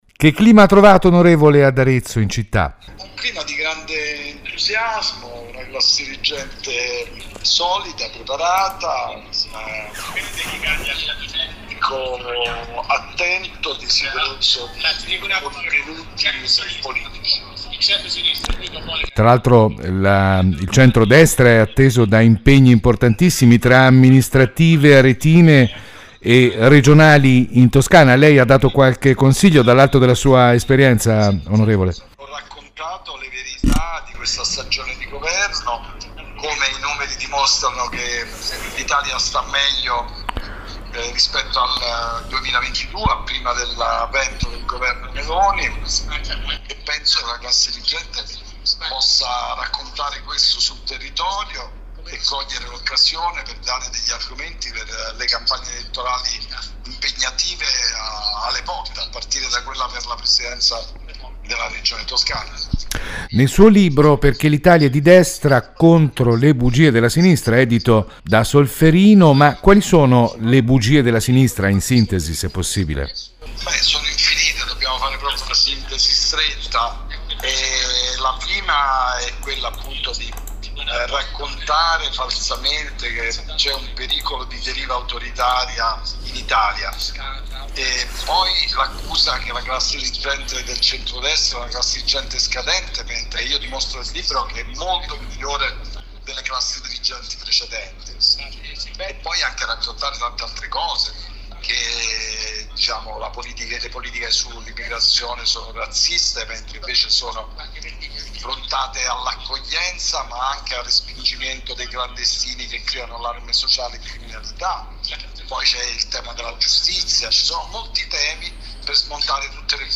L’intervista all’On. Italo Bocchino